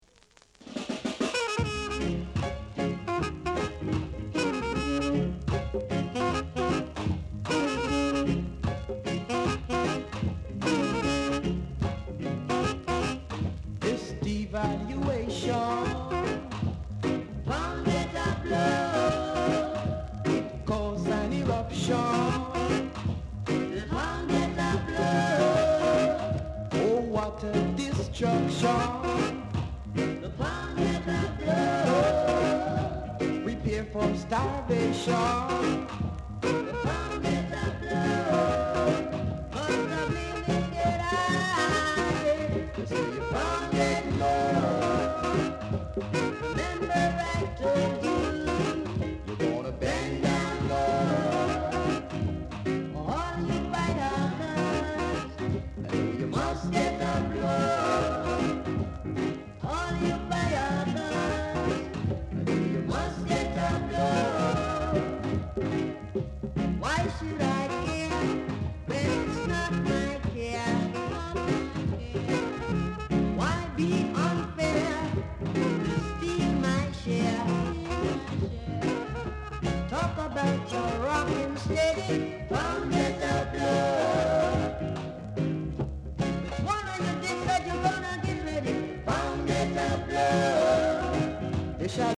R. Steady Vocal Group
Very rare! great rock steady vocal w-sider!